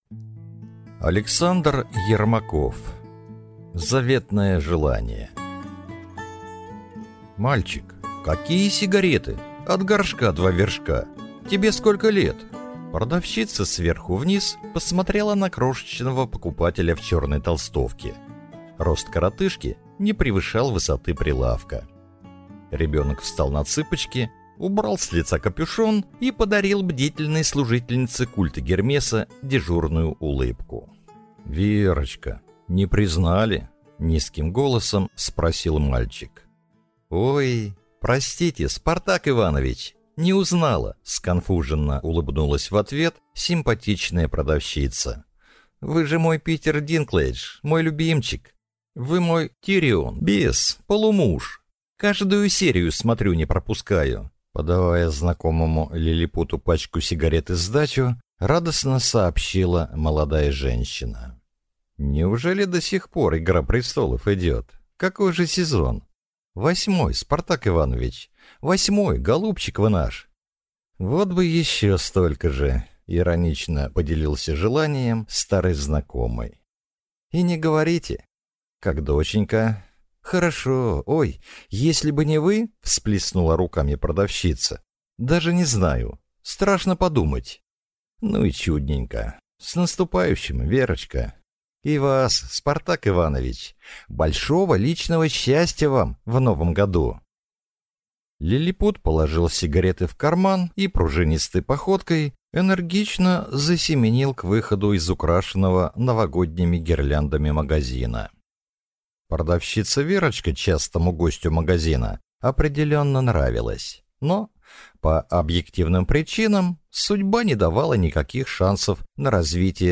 Аудиокнига Заветное желание | Библиотека аудиокниг